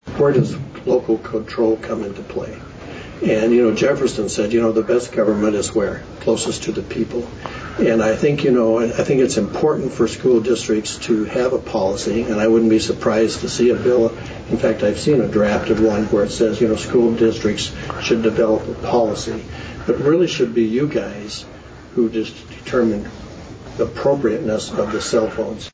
All three of the District 18 State Legislators addressed the Yankton School Board, last night.
State Representative, Mike Stevens, talks about local control versus state mandates…